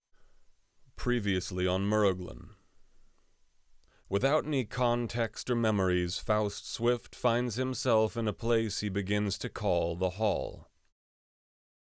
How do I remove that background noise..
Special Interest Groups Audiobook Production
I can’t seem to figure out how to remove the background noise from the recording.
The magic-ingredient you’re missing to remove the background noise is Mosquito Killer on 2 to get rid of the faint constant whine …